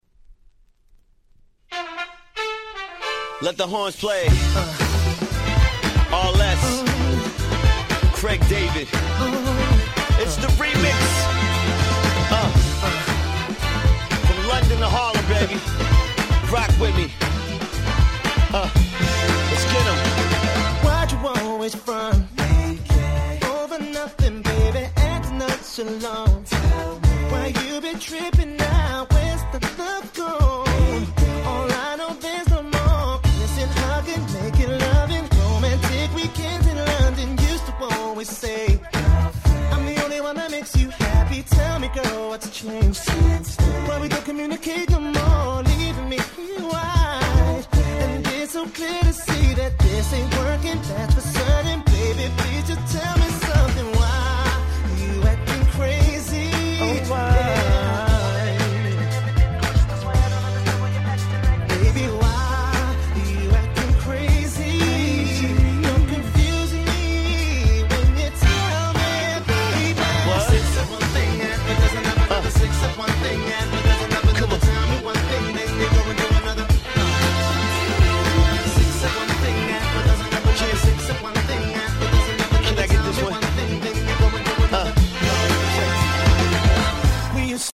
08' Nice R&B !!